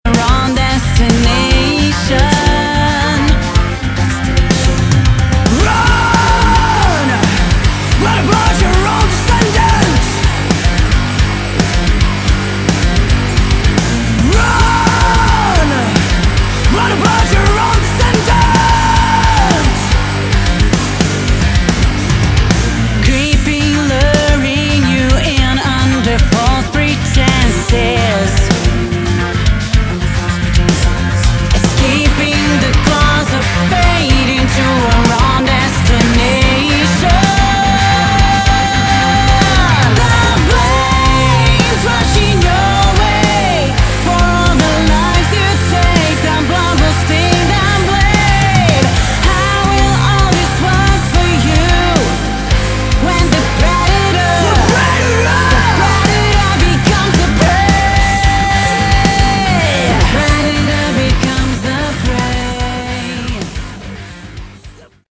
Category: Melodic Metal